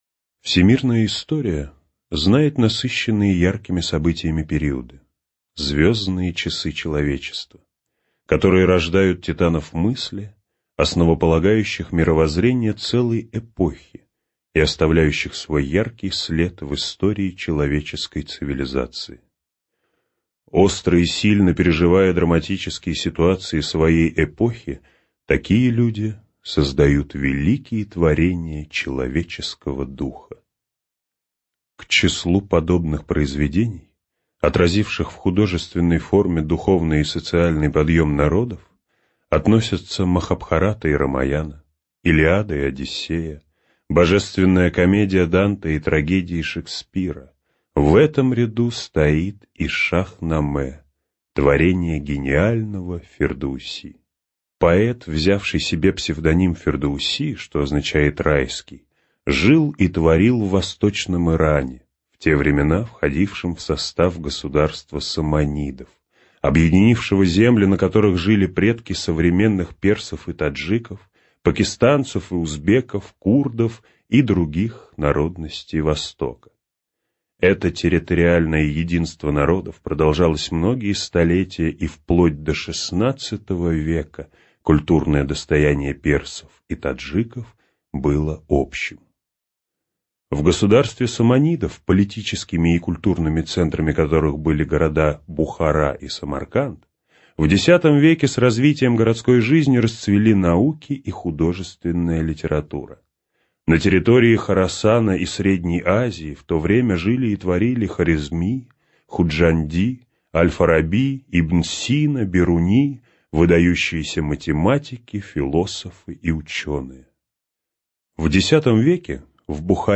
Студия звукозаписиРавновесие